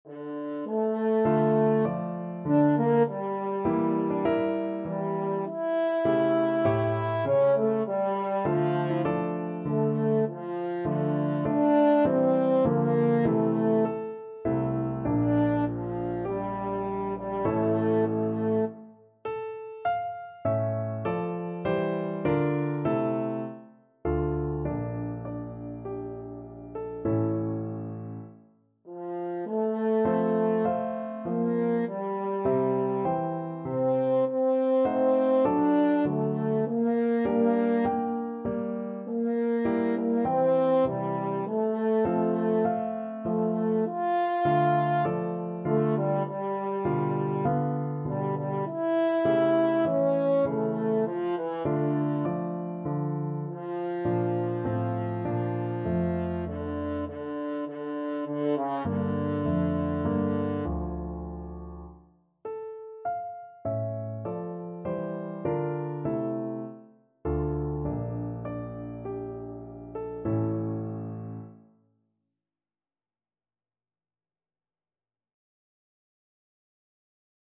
French Horn
Db4-F5
D minor (Sounding Pitch) A minor (French Horn in F) (View more D minor Music for French Horn )
4/4 (View more 4/4 Music)
Largo =c.100
Classical (View more Classical French Horn Music)